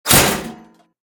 combat / weapons
metal3.ogg